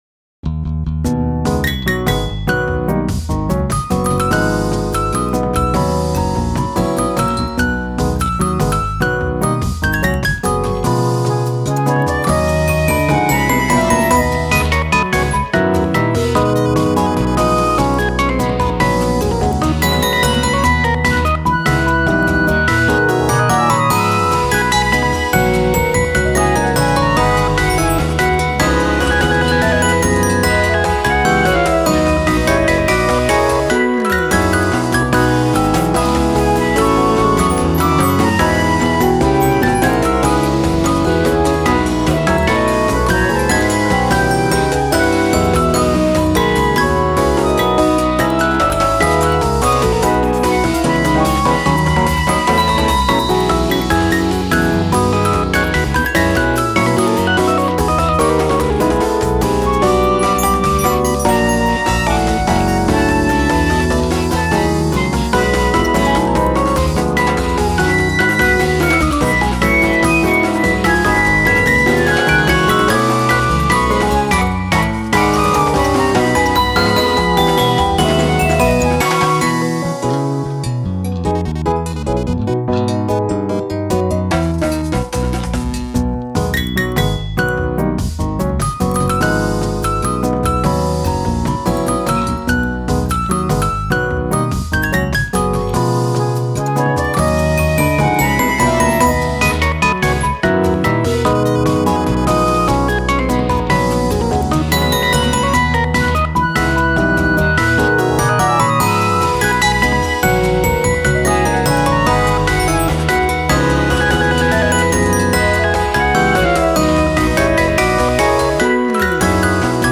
ゆったりした曲です。
つい混ぜてしまうファミコン音
タグ ゆったり　朝